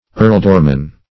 Earldorman \Earl"dor*man\, n.